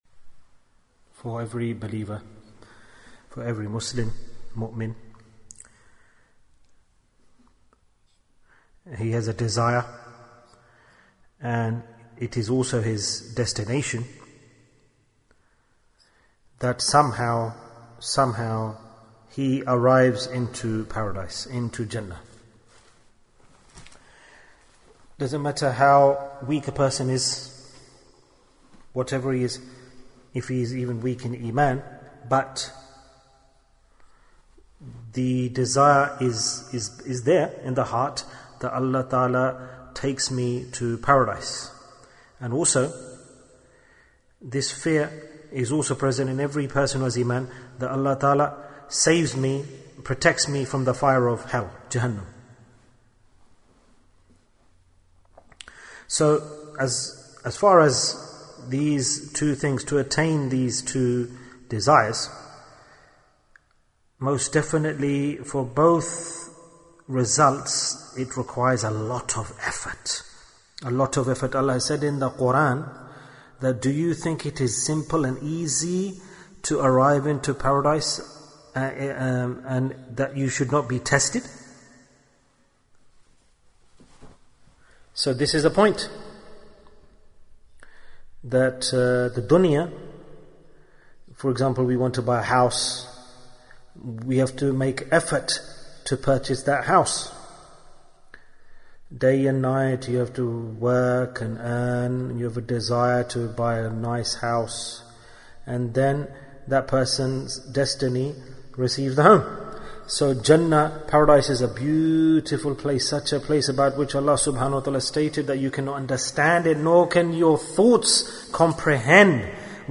Two Simple Things to Take You to Jannah Bayan, 23 minutes21st April, 2020